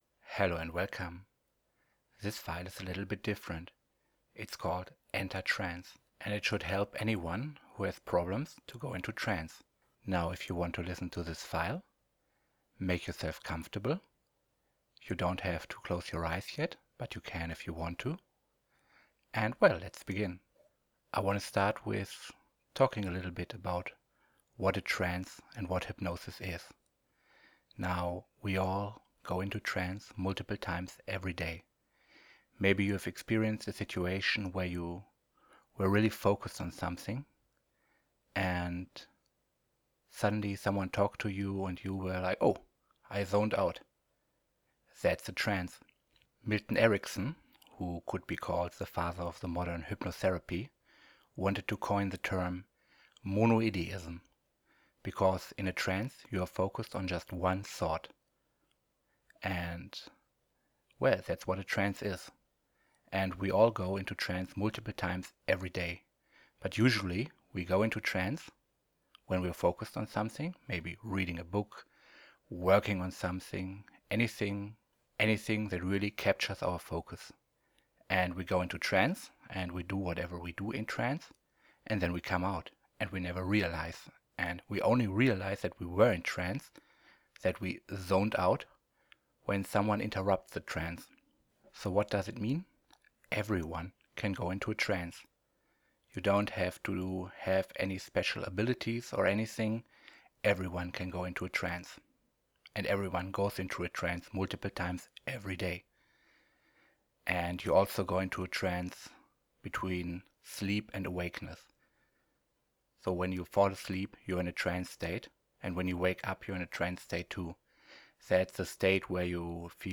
Enter Trance with this very very different hypnotic induction…